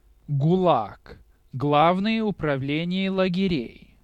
^ /ˈɡlɑːɡ/, UK also /-læɡ/; Russian: [ɡʊˈlak]